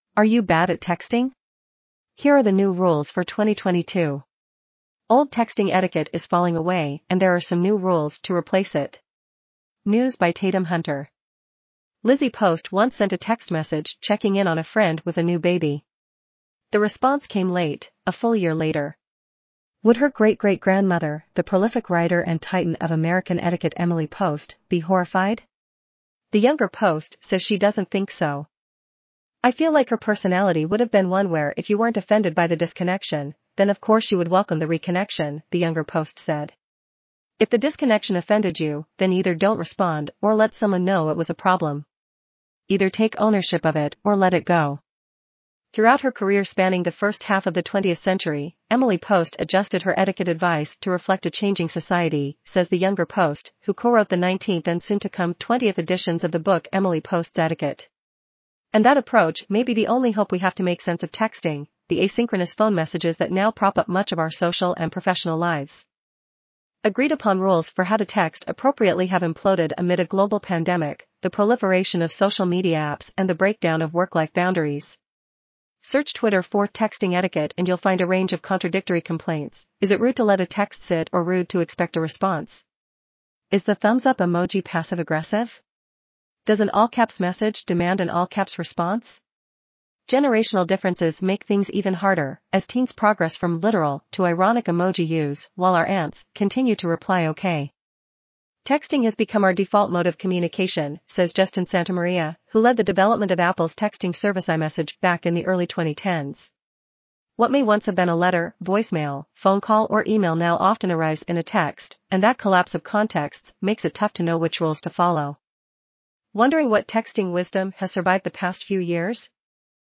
azure_en-US_en-US-JennyNeural_standard_audio.mp3